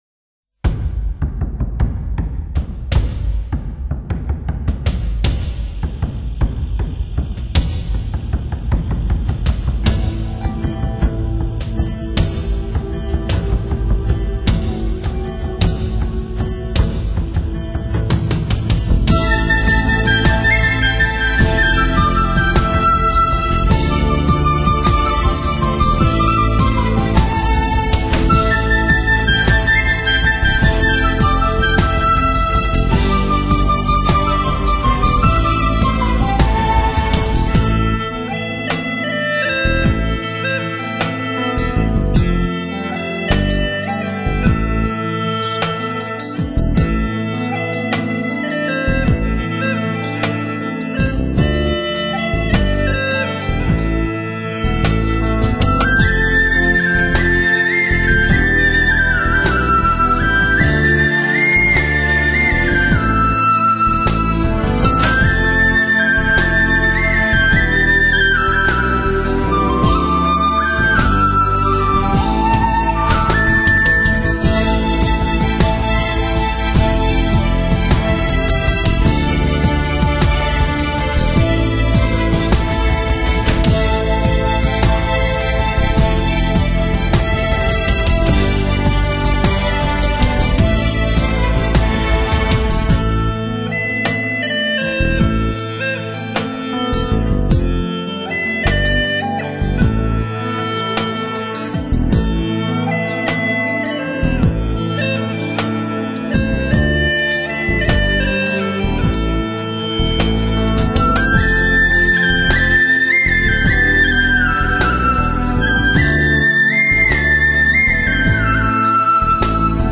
所有收录的乐曲均为原创，民间乐器自然古朴的原音与现代音乐表现手法相结合，揉合出耐人寻味的听觉效果。